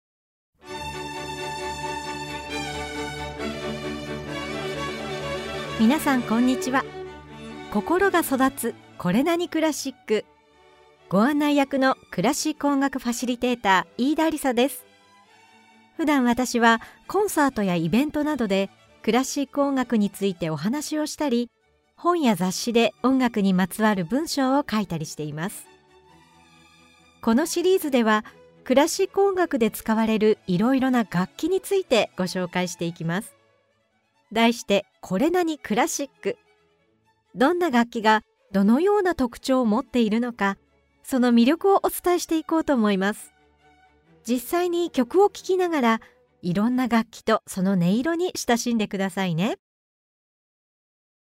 [オーディオブック] 心が育つ これなに？
実際に曲を聴きながら、いろんな楽器とその音色に親しんでください。
オーケストラでの打楽器の役割は、リズムを強調したり、ここぞというところで効果的な音を出したりと、とても多彩です。トルコの兵隊をイメージしたモーツアルトの作品、ティンパニとシロフォンが民族的な踊りを表現する「剣の舞」などを紹介しながら、打楽器のいろいろな音色とその魅力を紹介します！